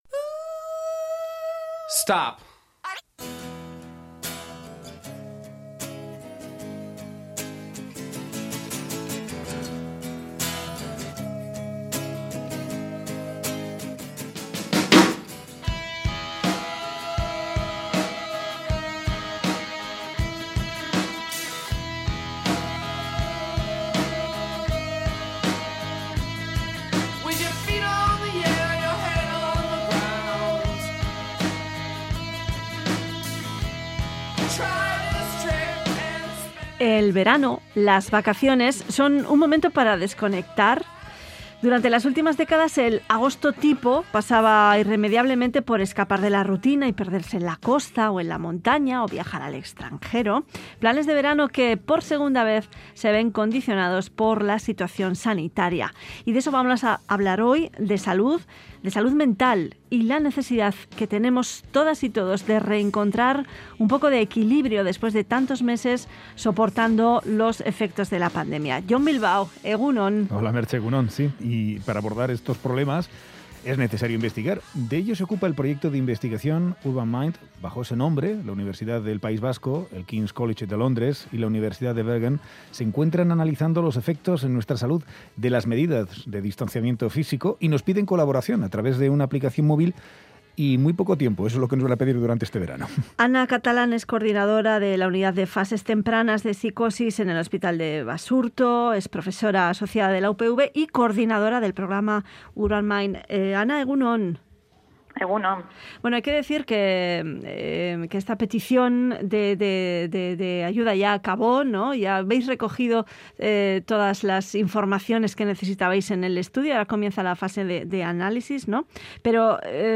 Audio: Conversamos